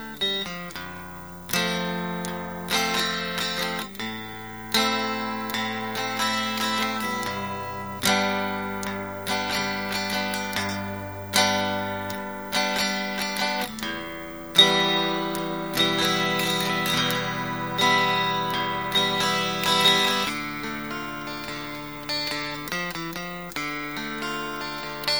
Ich selbst spiele meine Thinline gerne mal trocken. Anbei ein (sorry - dilettantisch mikrofoniertes) Klangbeispiel. Ich kann allerdings nur Thinlines generell, aber kein konkretes Modell empfehlen - das Klangbeispiel stammt von einem Eigenbau .